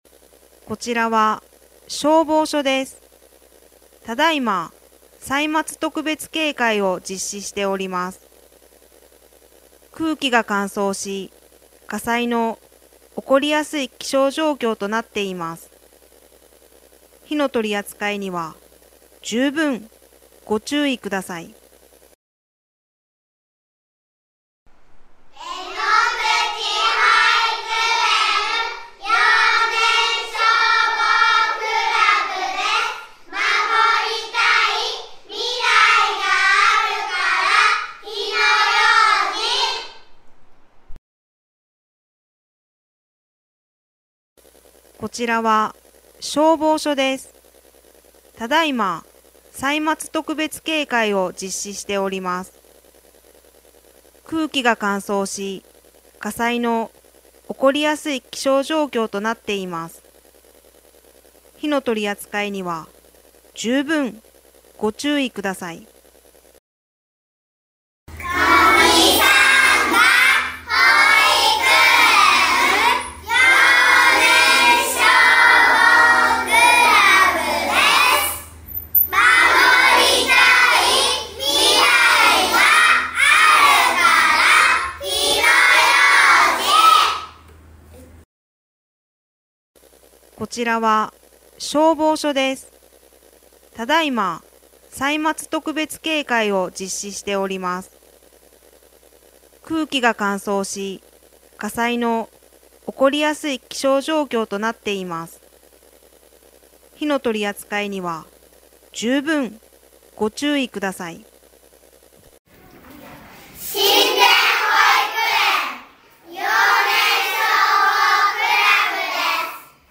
「火災予防運動」や「歳末警戒」の一環で、管内の子ども達（幼年消防クラブ員）が録音した音声を消防車から流し、パトロールをします。
子ども達は、かわいい元気いっぱいの声で「守りたい 未来があるから 火の用心」と音声を録音して協力して頂きました。
消防本部広報と結合バージョン（歳末警戒バージョン R6.12）